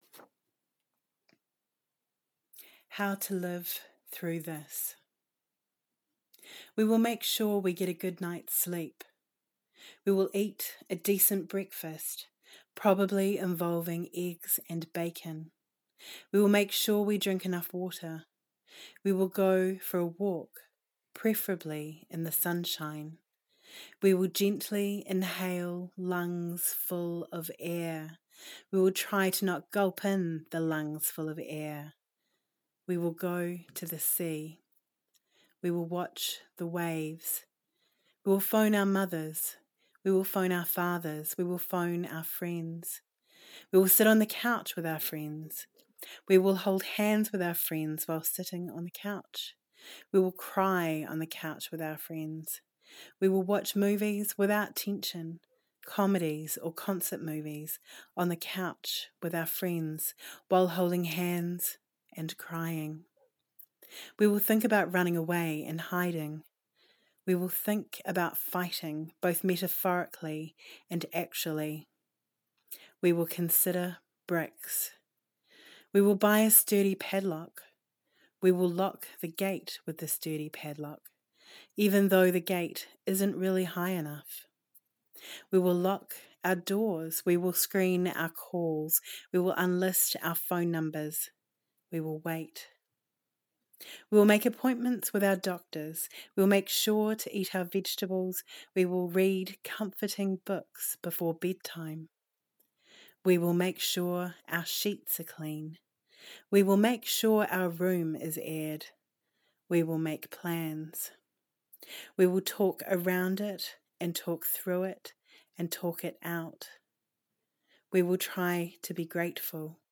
Poetry Shelf audio